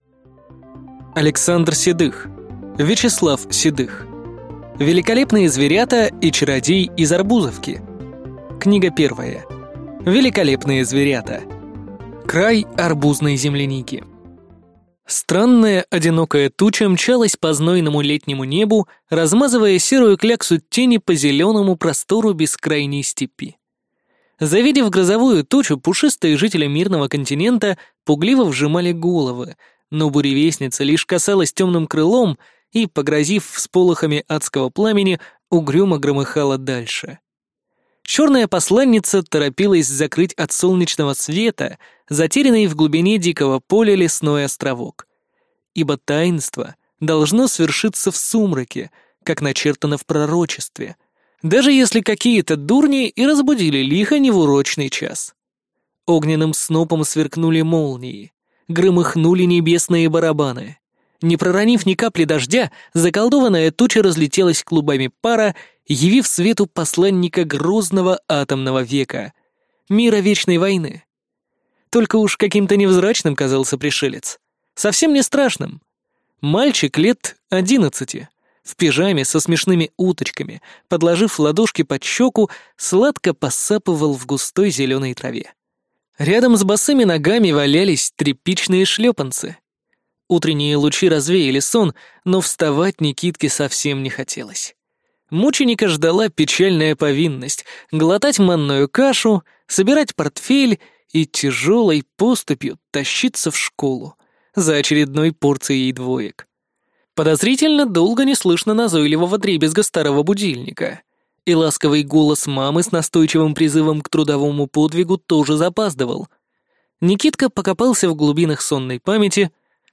Аудиокнига Великолепные зверята и чародей из Арбузовки. Книга 1. Великолепные зверята | Библиотека аудиокниг